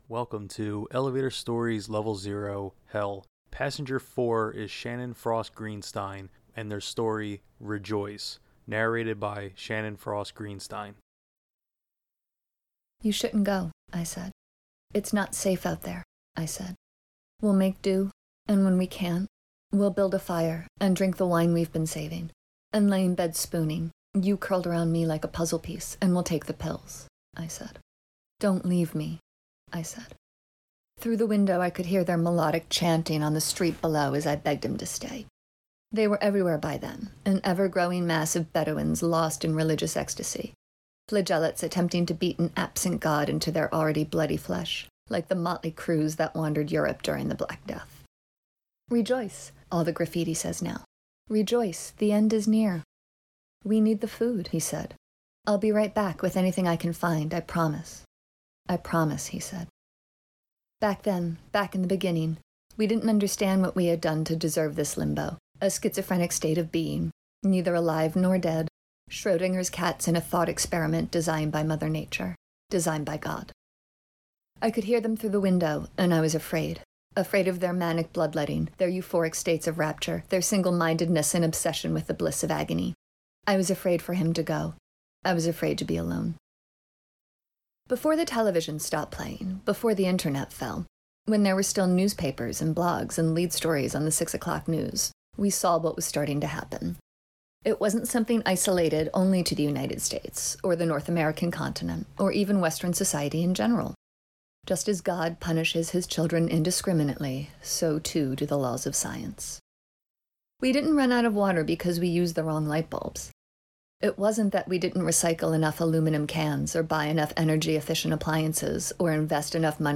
Rejoice! Audio Reading